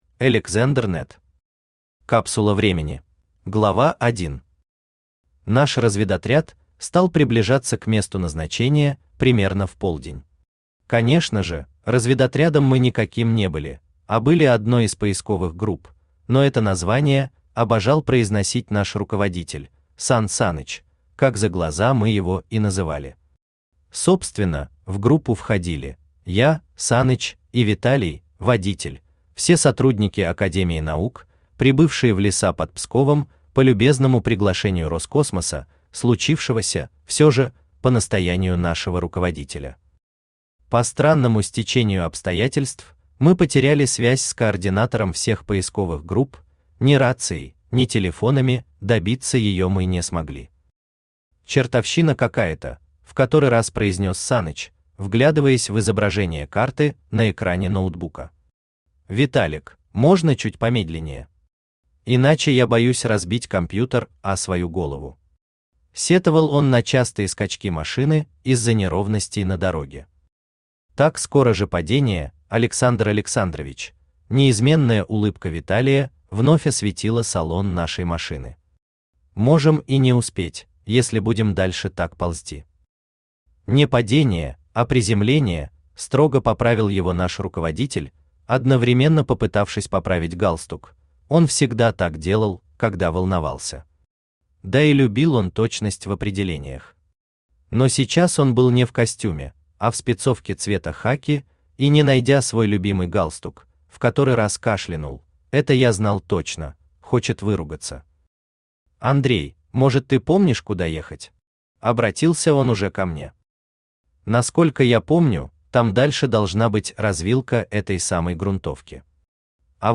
Аудиокнига Капсула времени | Библиотека аудиокниг
Aудиокнига Капсула времени Автор Alexander Ned Читает аудиокнигу Авточтец ЛитРес.